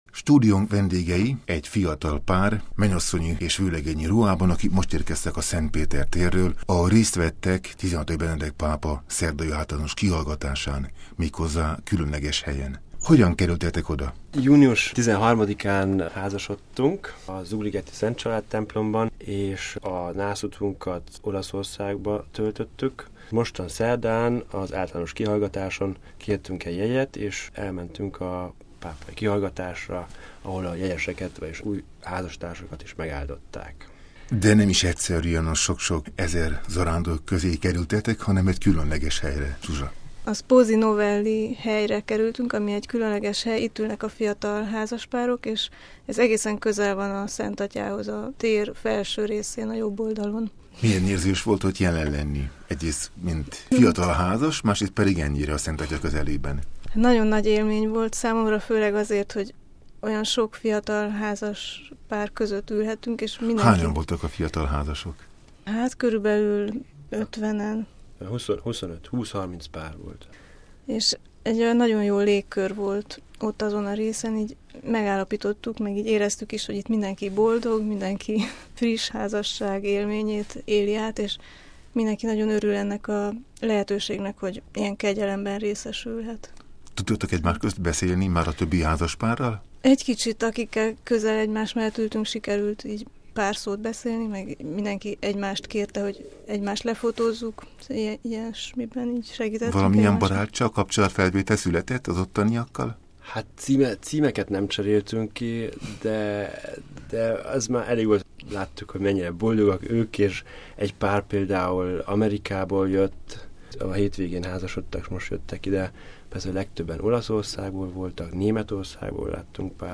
interjúját hallják